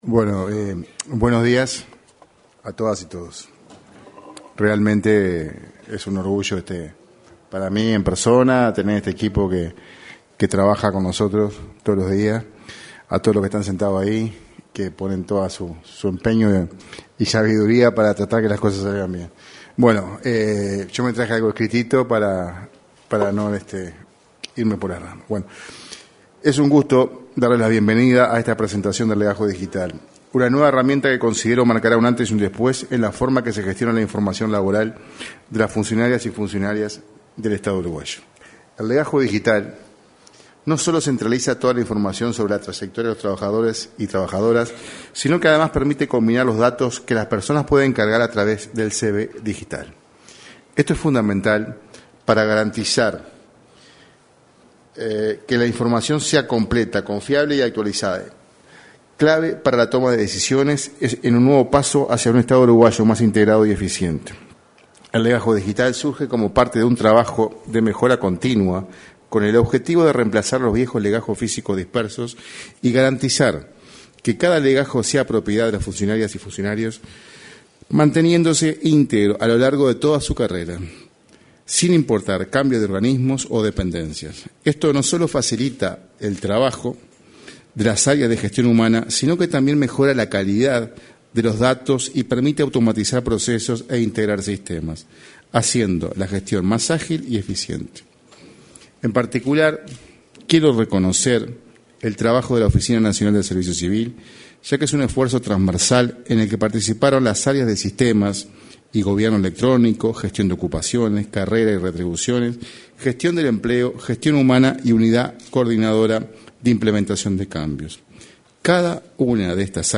Con motivo del lanzamiento del legajo digital, se expresó el director de la Oficina Nacional del Servicio Civil, Sergio Pérez.